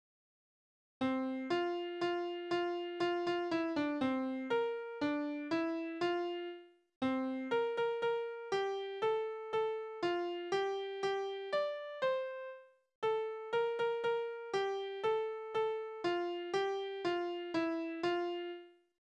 Schelmenlieder: Besenbinders Tochter ist Braut
Tonart: F-Dur
Taktart: 3/4
Tonumfang: große None
Besetzung: vokal